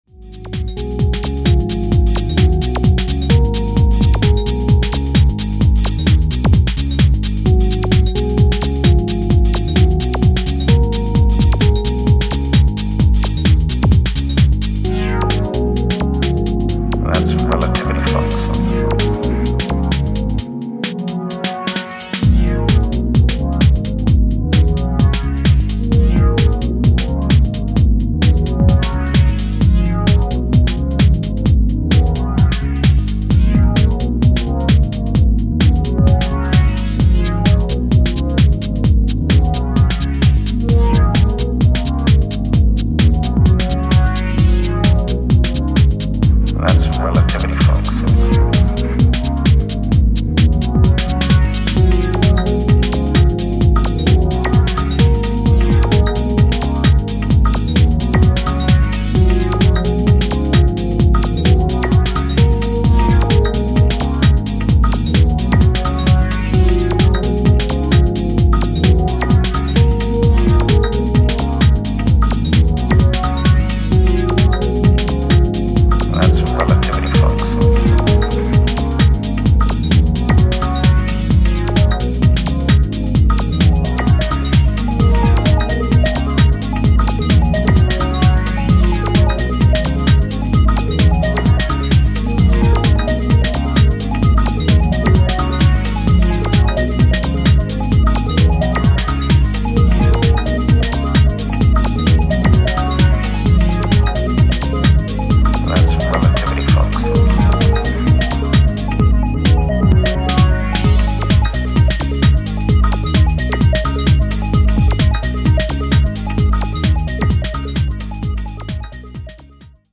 gives 4 tracks for the floor
Breakbeat , House , Techno